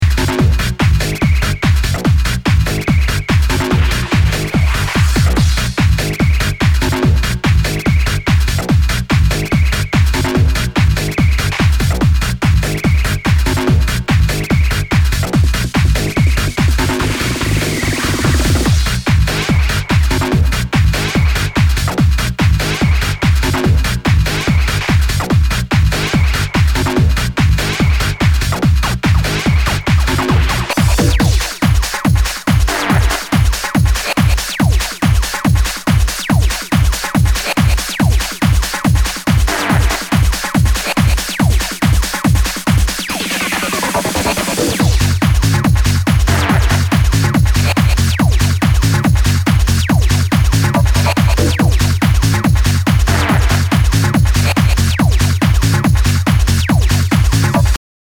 HOUSE/TECHNO/ELECTRO
ナイス！ハード・ハウス！